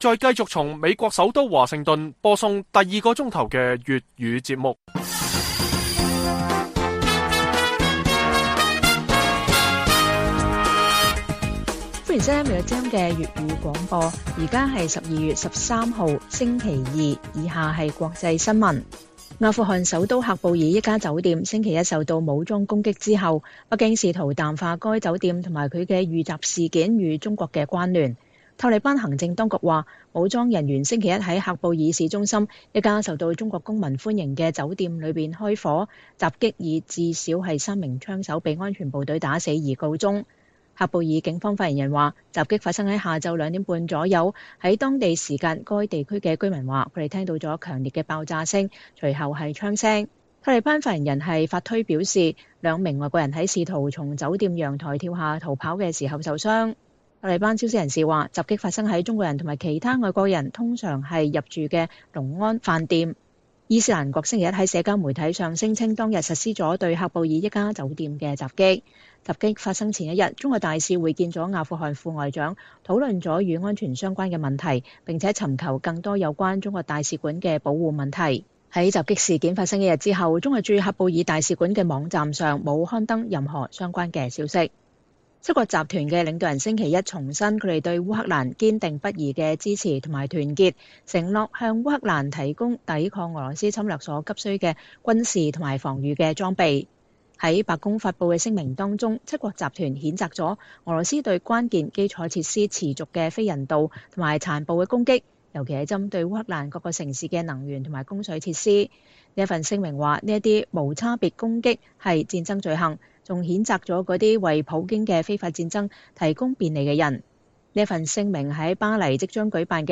粵語新聞 晚上10-11點: 喀布爾中國人聚集的酒店遭遇“恐怖襲擊” 北京看似淡化與事件關